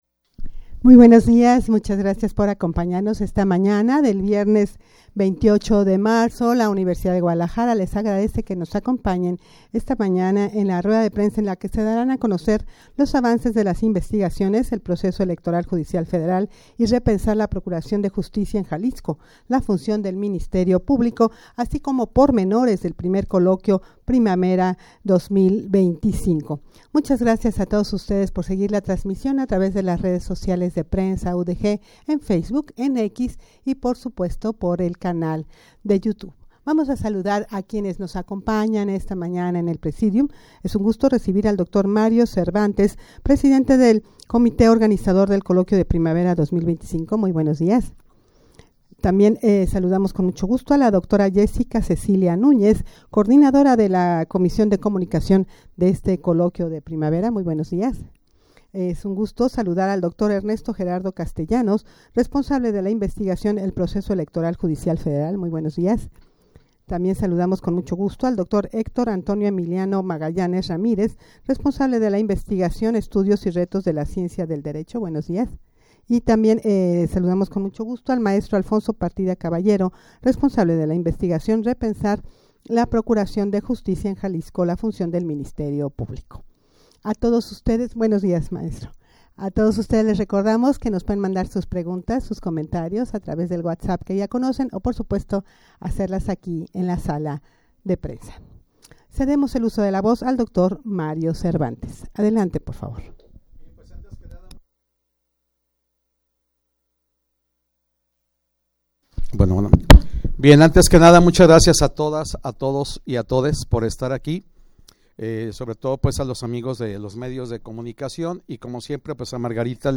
rueda-de-prensa-para-dar-a-conocer-los-avances-de-las-investigaciones-el-proceso-electoral-judicial-federal-y-repensar-la-procuracion-de-justicia-en-jalisco.mp3